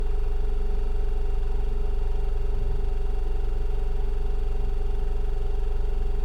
Vehicles, Electric, Car, Tesla, Model 3, Idle, Engine Compartment 01 SND66191.wav